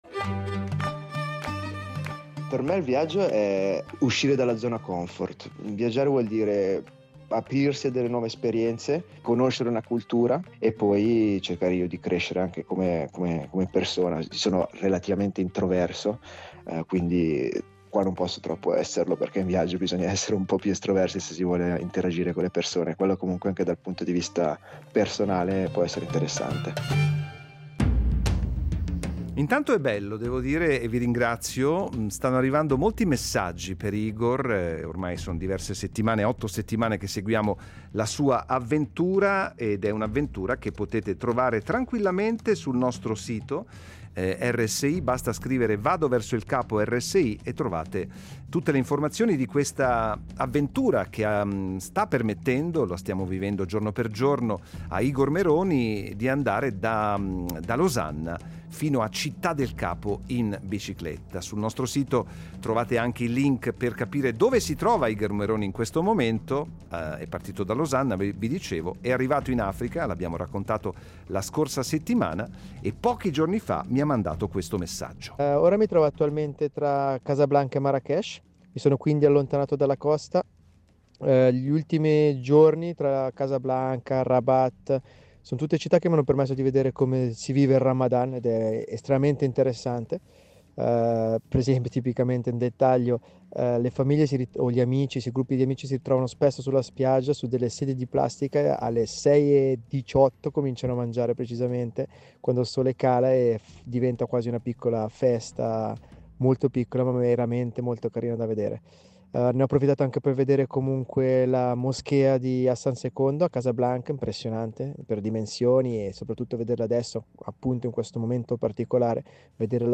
Giorno 65, 50 km a nord di Marrakech, Marocco
In questo episodio qualche cartolina sonora, tra Casablanca e Marrakash.